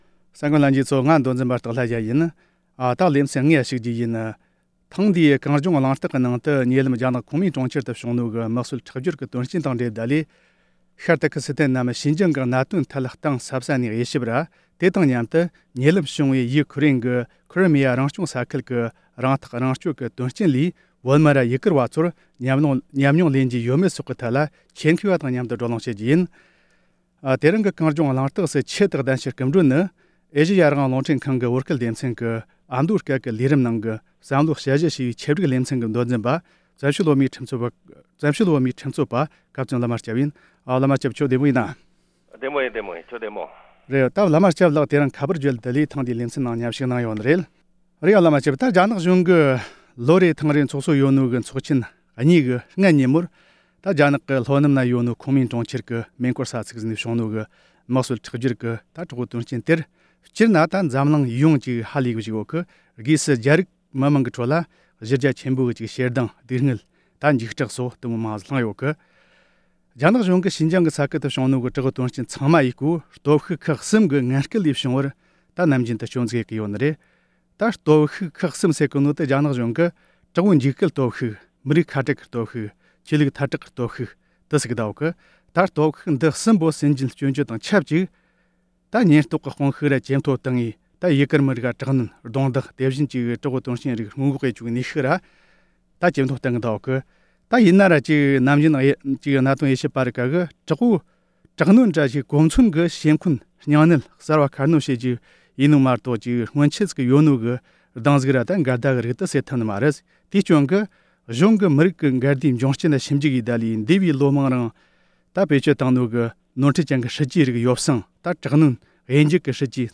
ཉེ་ལམ་རྒྱ་ནག་ཁོན་མེན་གྲོང་ཁྱེར་དུ་བྱུང་བའི་མི་གསོད་ཁྲག་སྦྱོར་གྱི་དོན་རྐྱེན་སོགས་དང་འབྲེལ་བའི་ཐད་བགྲོ་གླེང༌།